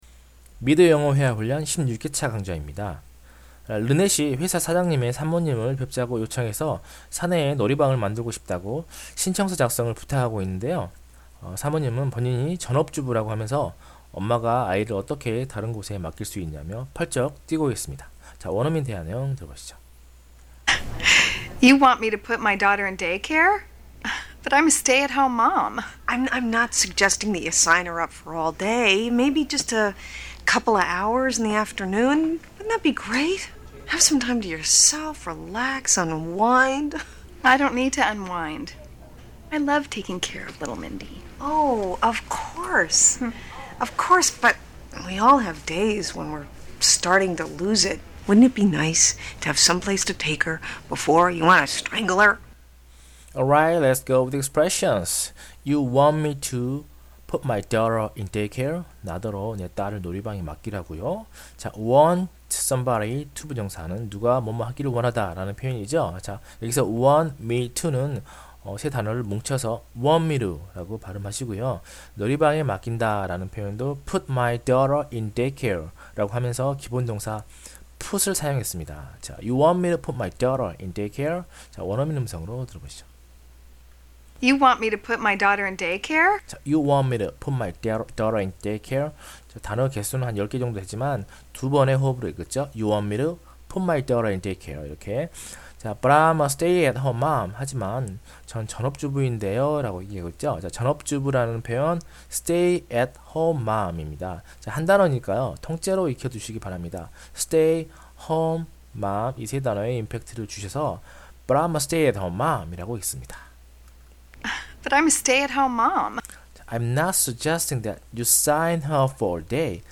<팟캐스트 해설강의>
16회차해설강의.mp3